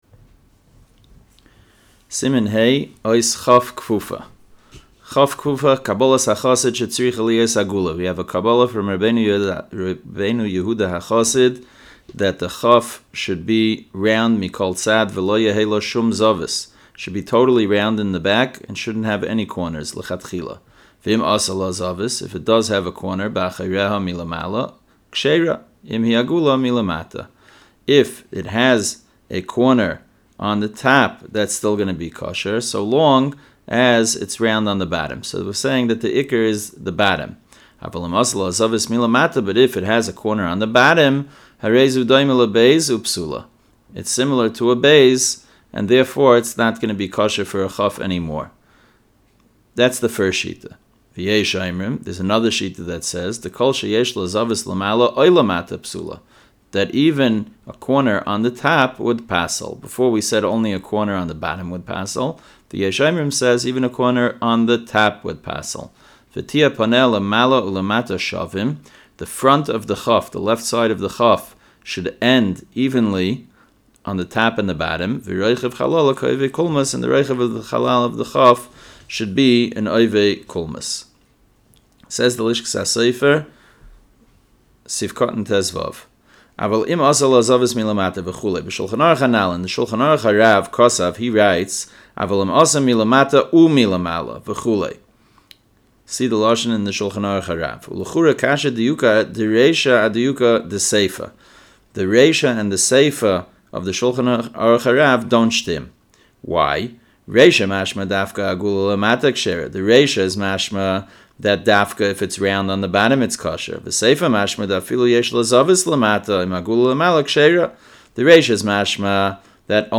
Audio Shiurim - The STa"M Project | Kosher-Certified Mezuzos, Tefillin & STa”M